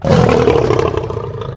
lion2.ogg